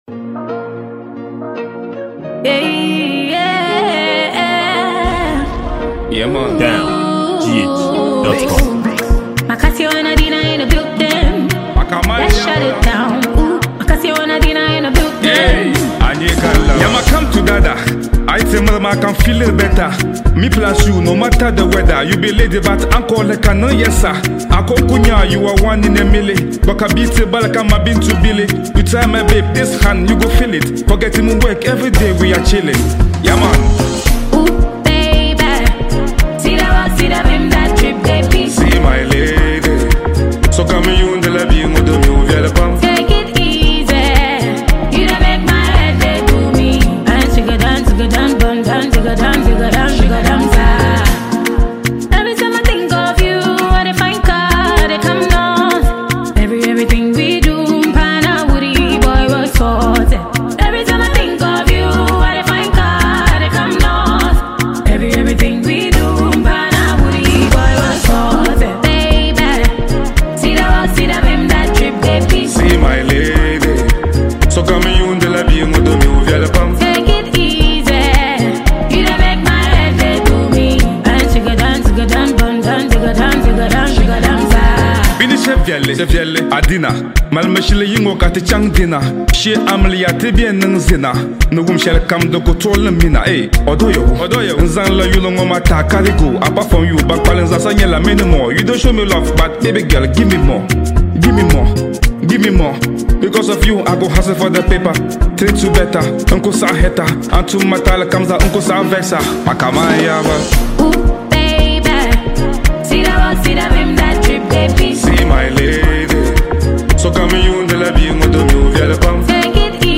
a captivating Ghanaian song released by top-notch musician